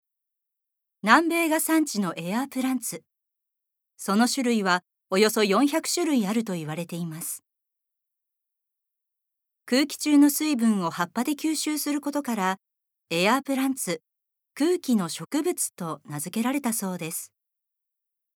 Voice Sample
ナレーション１